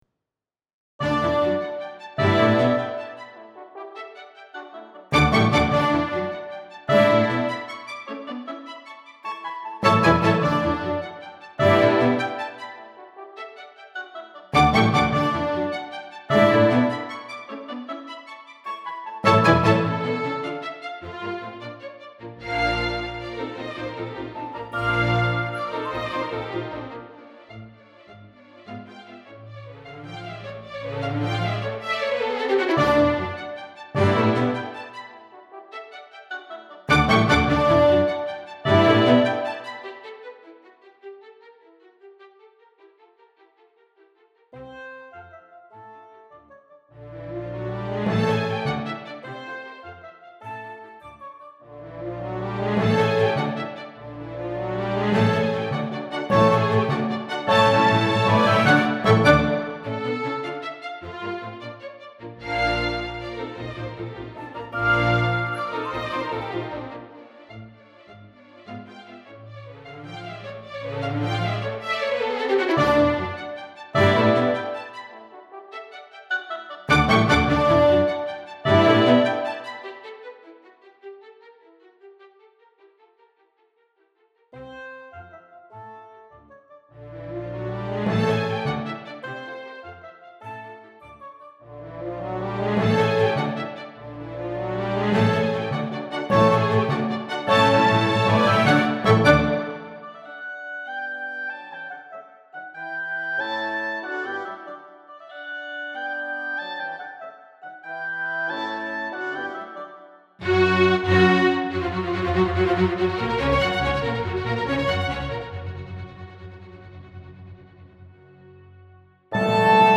Performed live at Brandeis University